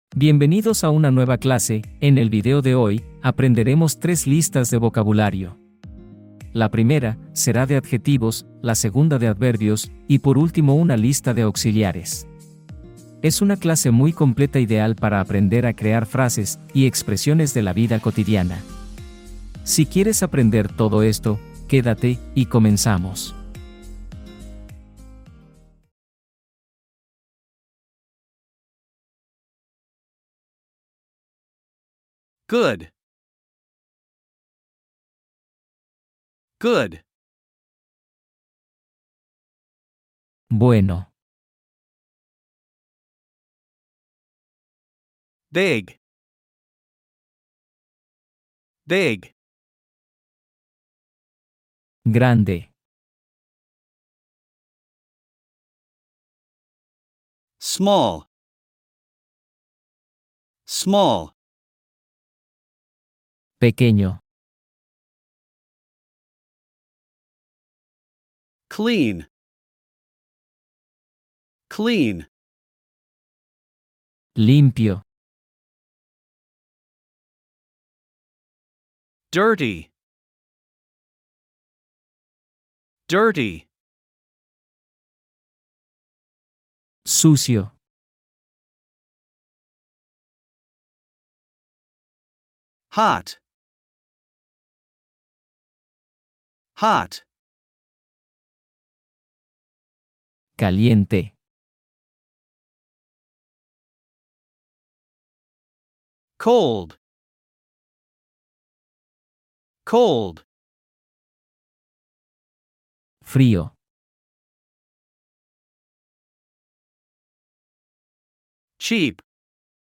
Mega lección: adjetivos, verbos y adverbios explicados